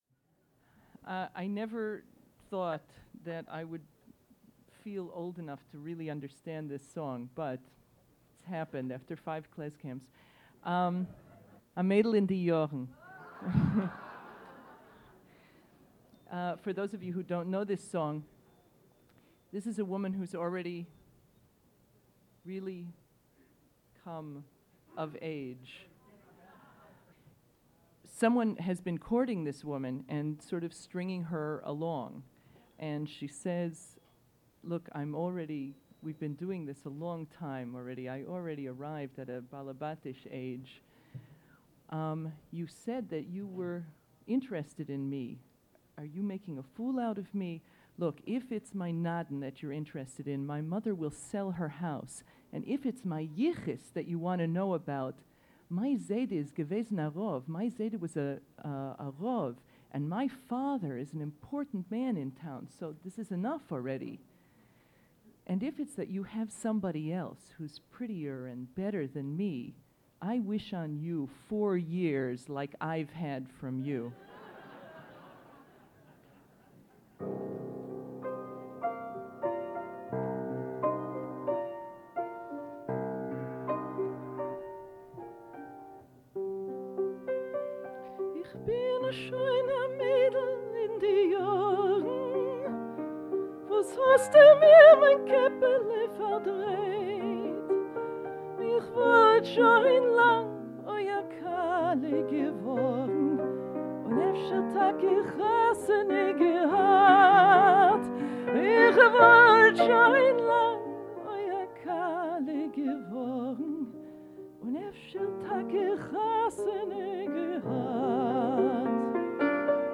piano
Recorded at KlezKamp: The Yiddish Folk Arts Program